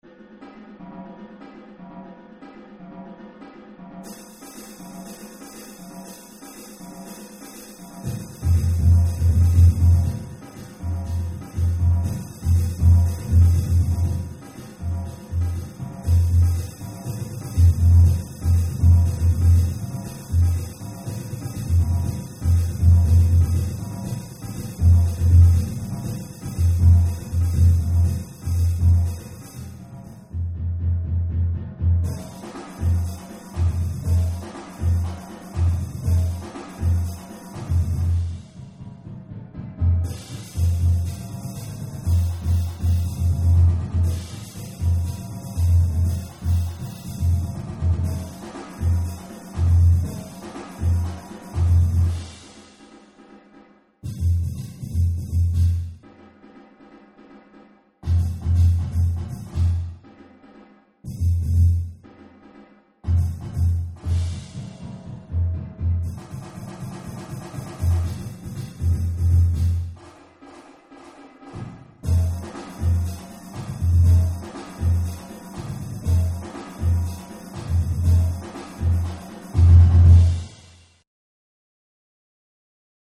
Multi-Percussion
Snare drum Quad Toms Cowbell Cymbals Bass drum